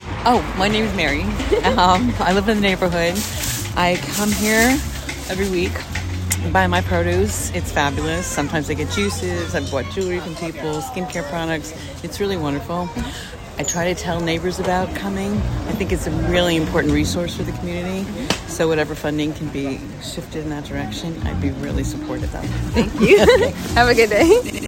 Listen to testimonials from our community members.